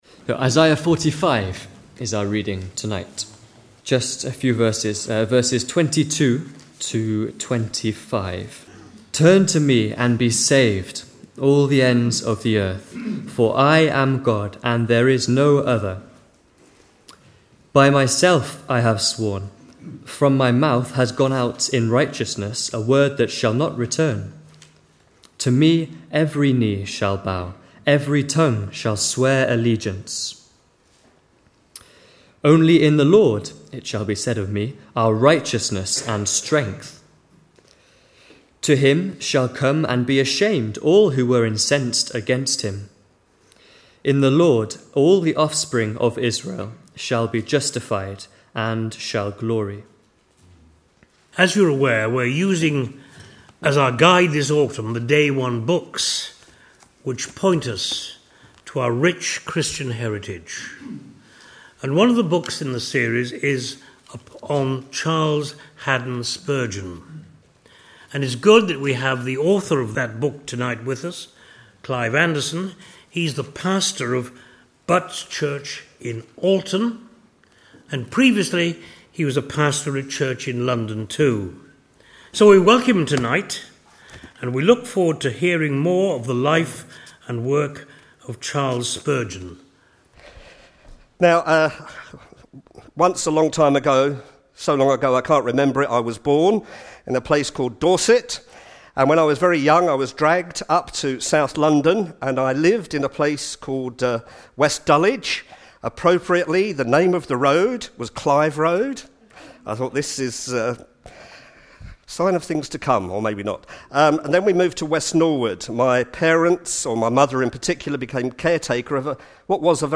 C H Spurgeon is one of the most influential preachers of our time, with his books still being read by millions of people across the world over a century after his death. This illustrated lecture will look at the life of Charles Spurgeon from his early years as a country preacher through to Spurgeon as the pastor of the largest congregation in the capital of the British Empire.